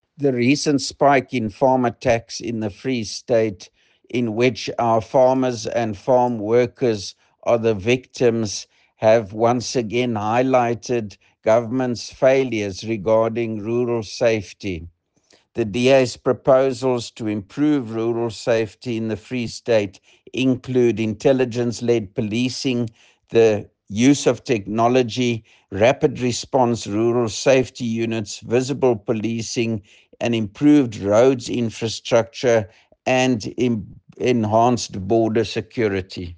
English and Afrikaans soundbites by Roy Jankielsohn MPL, and